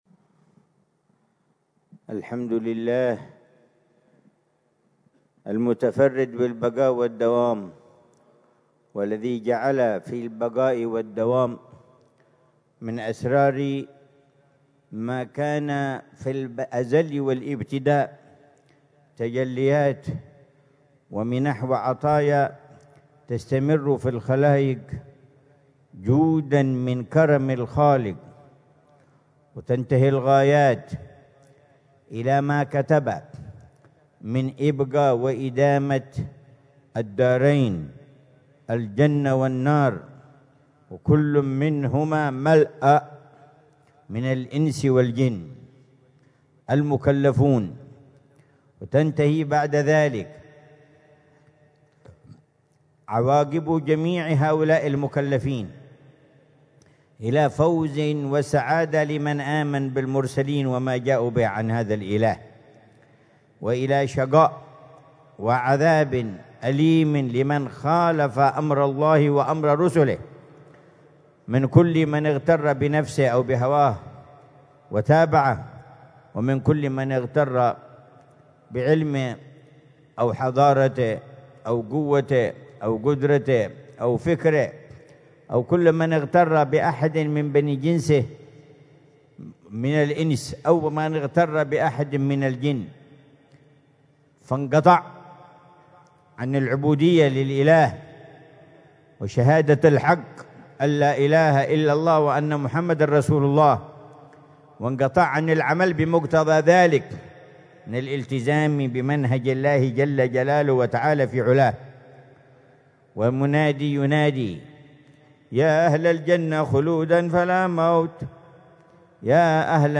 محاضرة العلامة الحبيب عمر بن محمد بن حفيظ ضمن سلسلة إرشادات السلوك في دار المصطفى، ليلة الجمعة 4 ربيع الثاني 1447هـ، بعنوان: